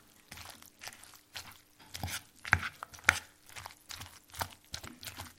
Откройте для себя мир звуков чеснока: хруст при нарезке, шорох шелухи, сочный треск под прессом.
Чеснок смешали со сметаной и приготовили ароматный соус